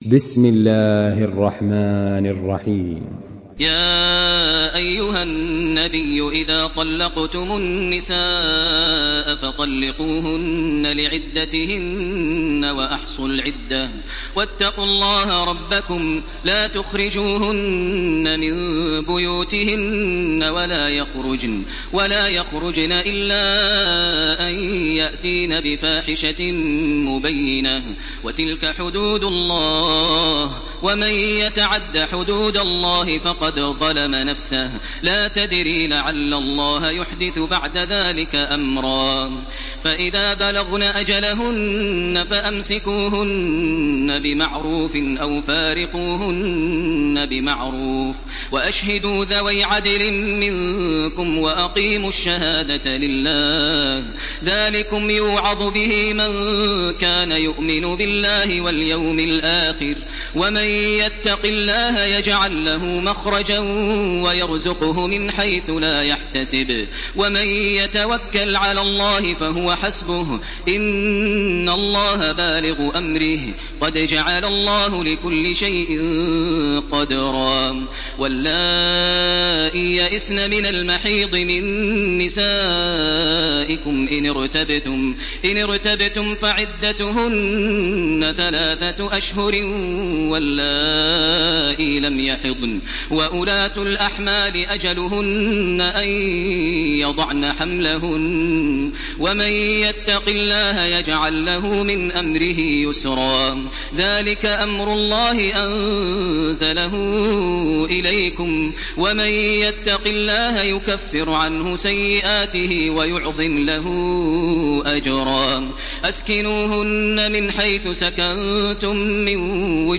تراويح الحرم المكي 1429
مرتل حفص عن عاصم